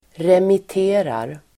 Uttal: [remit'e:rar]